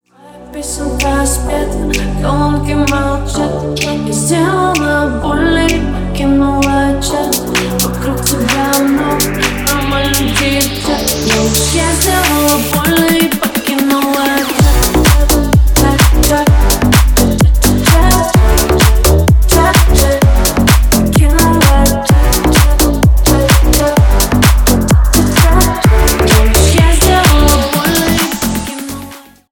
бесплатный рингтон в виде самого яркого фрагмента из песни